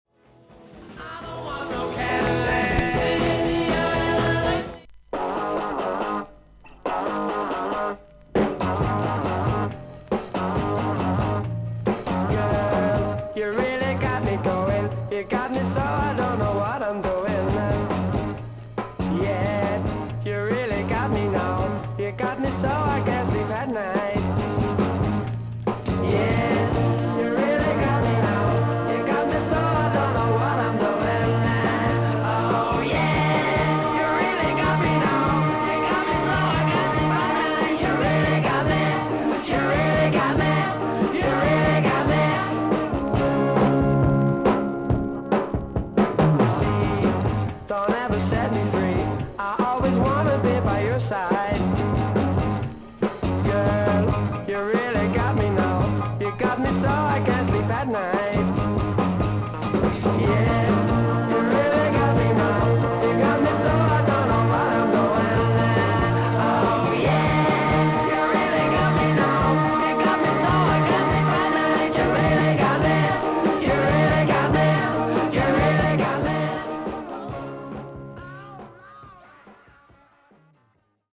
ほかはVG+〜VG++:少々軽いパチノイズの箇所あり。少々サーフィス・ノイズあり。クリアな音です。